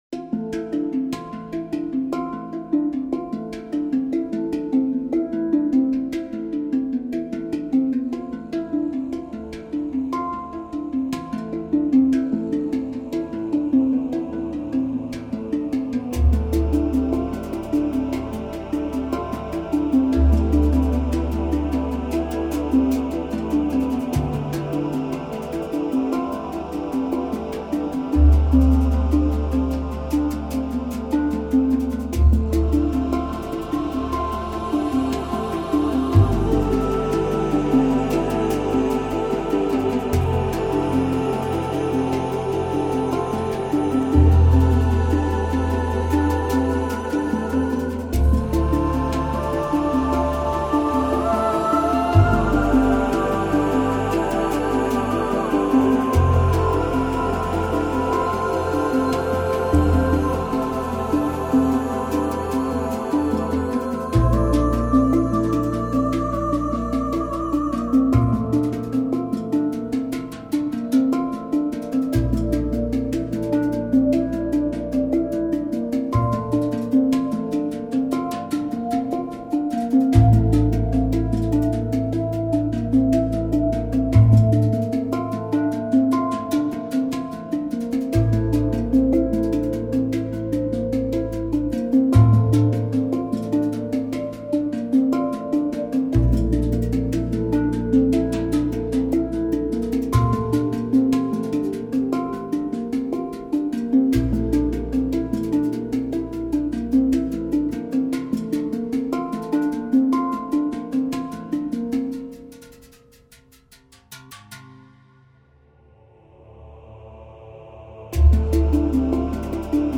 variation for chorus